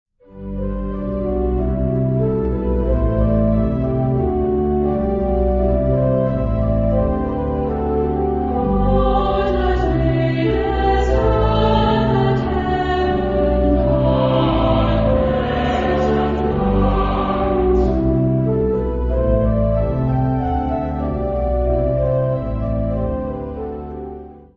Genre-Style-Forme : Sacré ; Motet ; Hymne (sacré)
Caractère de la pièce : calme
Type de choeur : SAH  (3 voix mixtes )
Instruments : Piano (1)
Tonalité : sol majeur